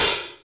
HH HH231.wav